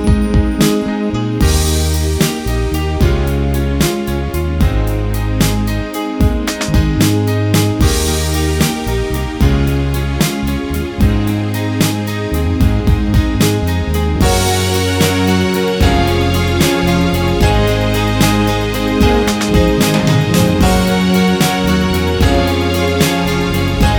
no Backing Vocals Pop (2010s) 3:18 Buy £1.50